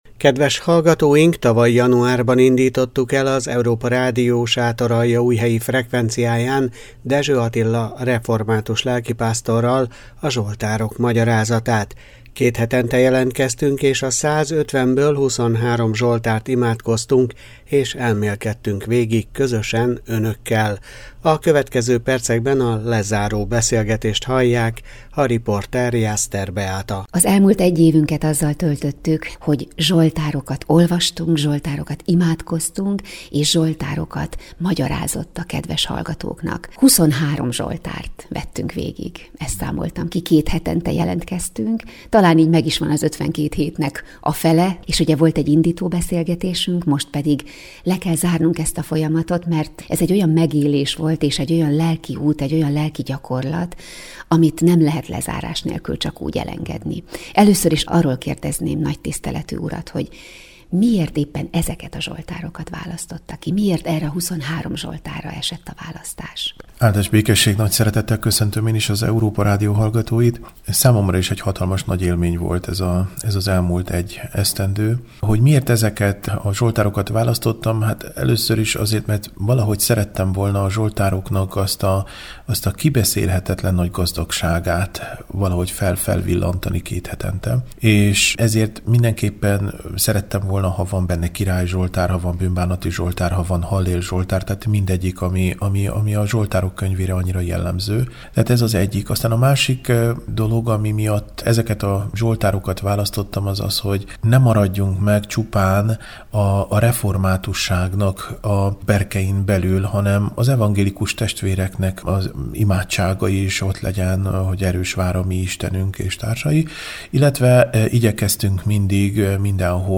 A következő percekben a lezáró beszélgetést hallják.